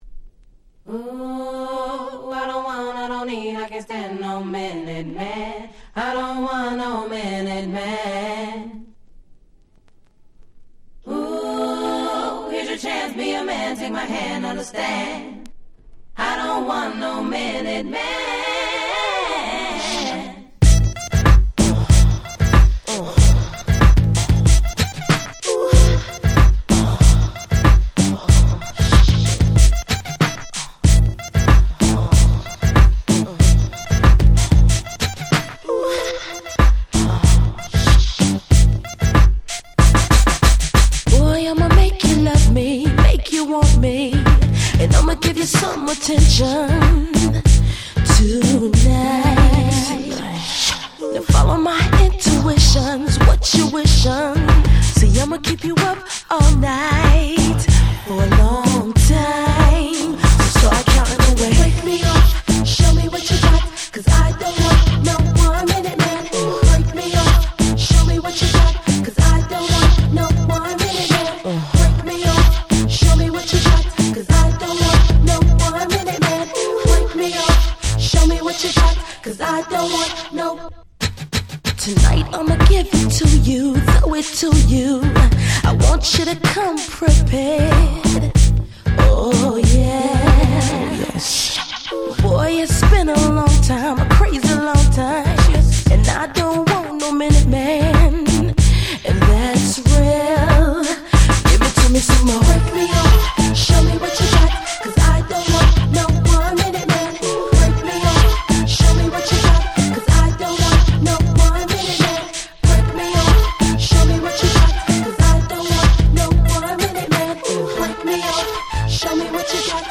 01' Super Hit Hip Hop !!